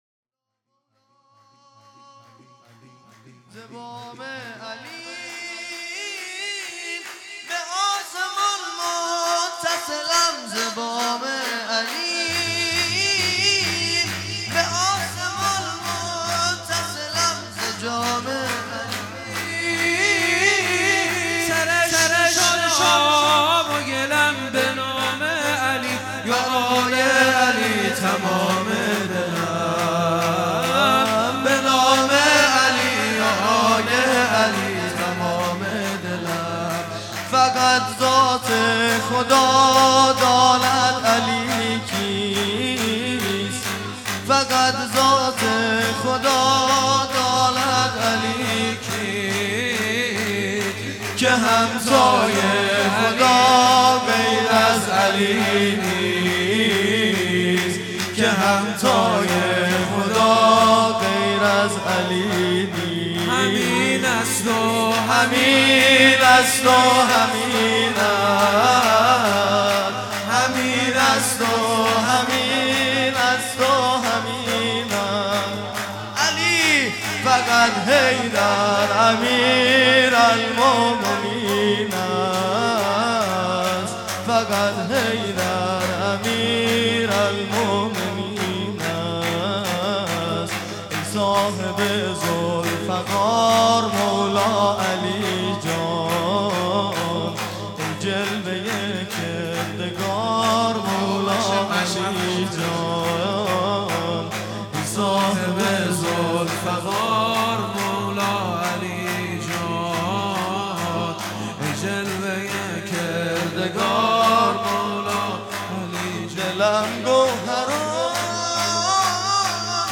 هیئت دانشجویی فاطمیون دانشگاه یزد
سرود
ولادت امام باقر (ع) | ۱۴ اسفند ۹۷